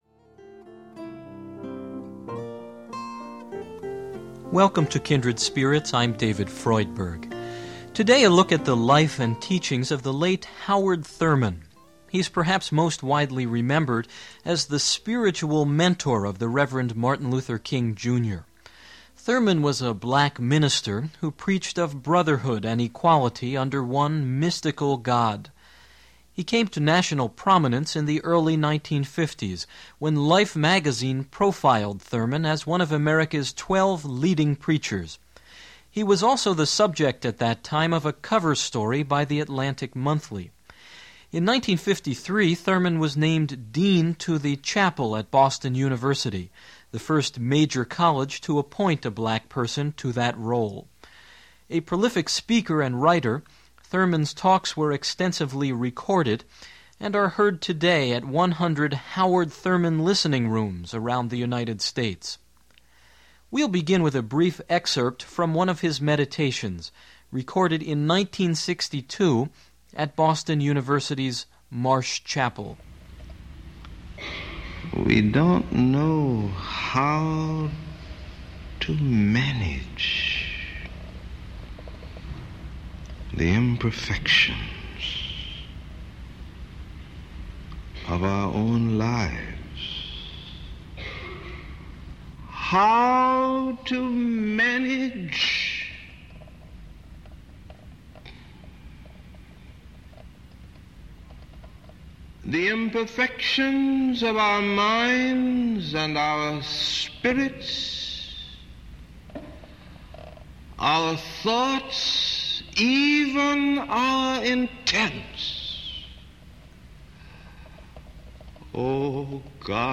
A Meditation by Howard Thurman
ks2-2a_meditation_howard_thurman.mp3